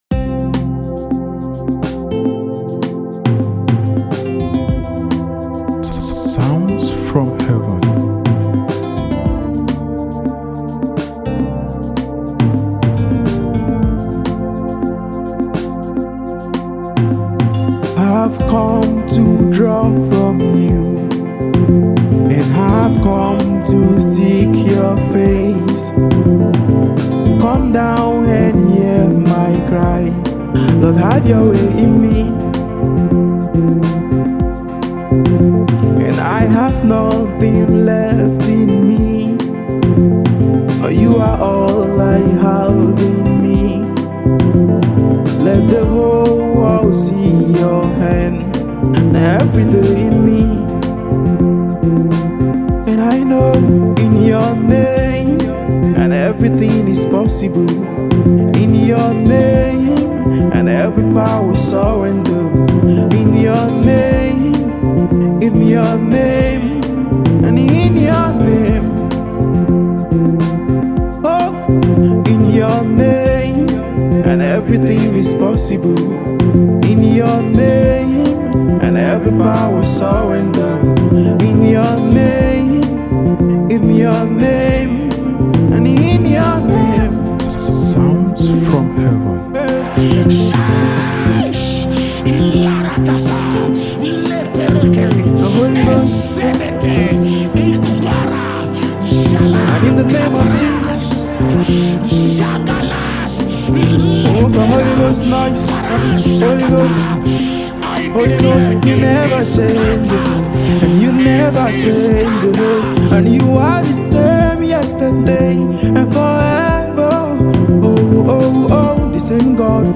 Contemporary Gospel singer